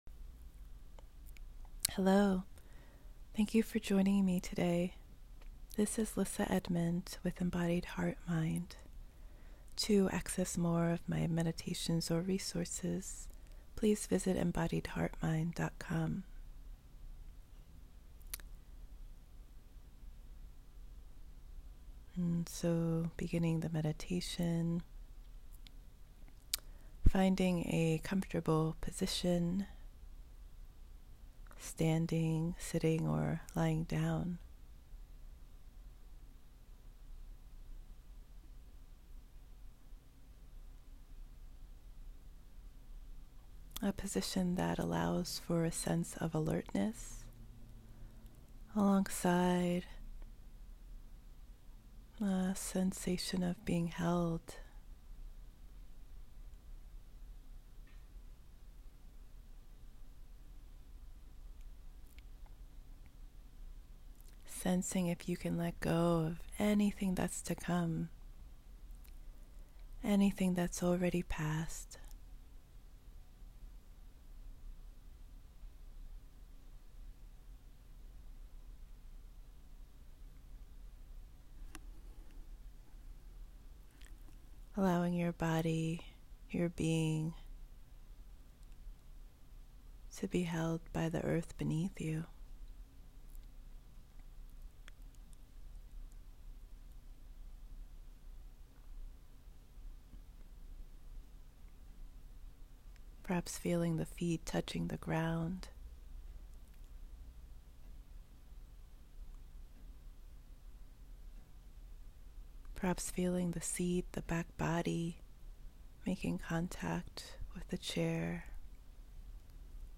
alone together & not knowing together resources + guided benefactor practice
benefactor-receiving-practice.m4a